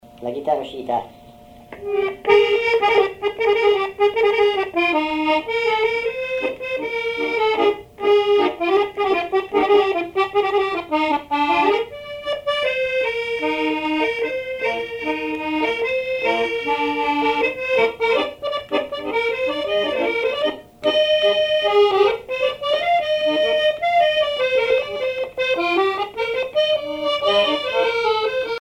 accordéon(s), accordéoniste
danse : paso musette
Répertoire à l'accordéon chromatique
Pièce musicale inédite